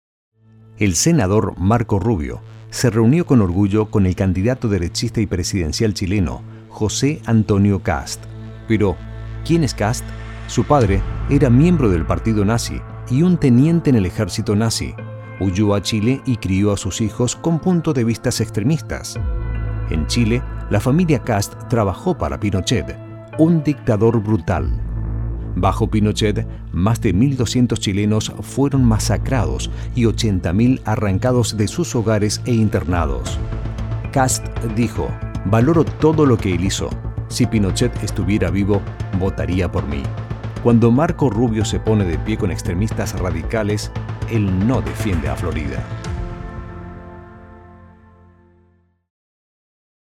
Spanish-speaking male voice actor
Spanish-Speaking Men, Political